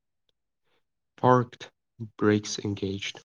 parked-brakes-engaged.wav